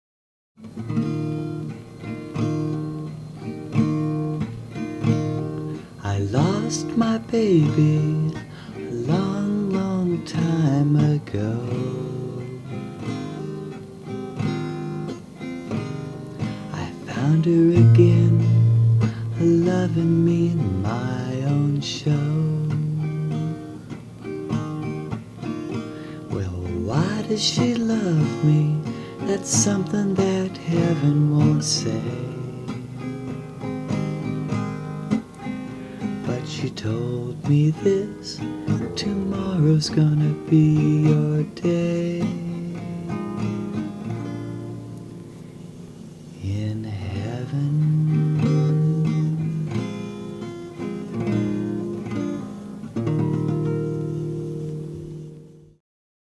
R & B